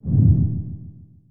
snd_dtrans_heavypassing.ogg